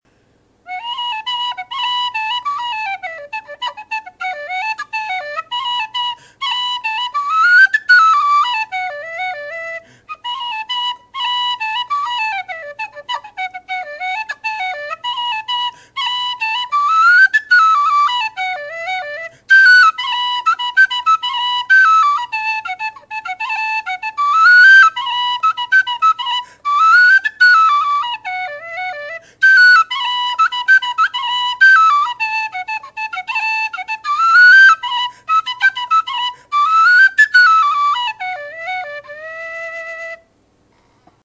Sound clips of the whistle:
Tone: Strong bottom end, with a little bit of windiness. The hissing windiness increases as you go up into the second octave.
Volume: Louder than average.